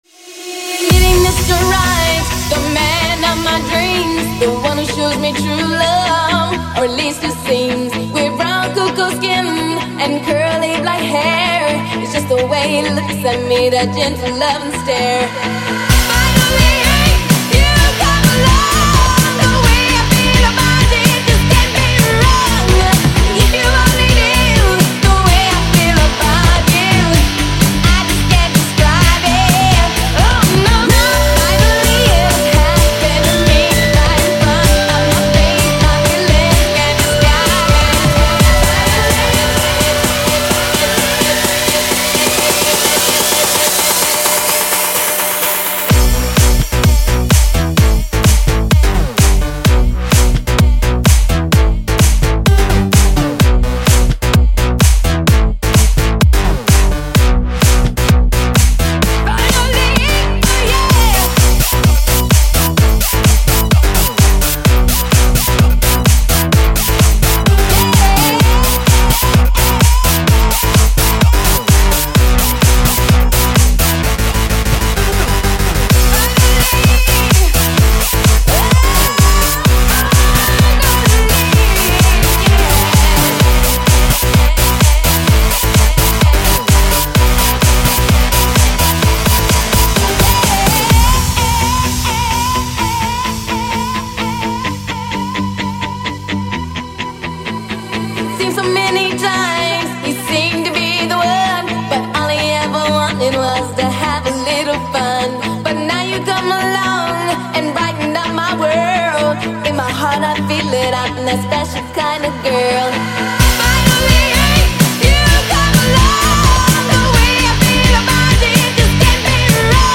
Стиль: Electro House